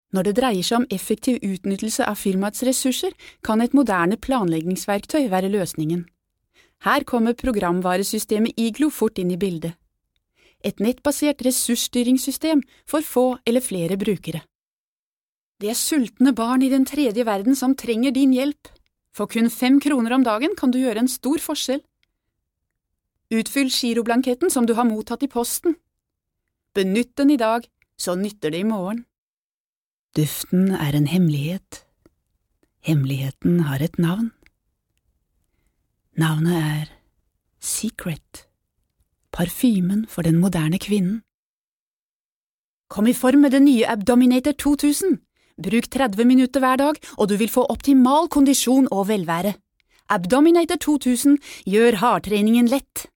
Sprecherin norwegisch (Muttersprache) Sprecherin für Trickfilme, Werbung, Dokumentationen uvm.
Sprechprobe: Werbung (Muttersprache):
Norvegian female voice over artist.